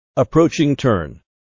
tankerTurnSound.ogg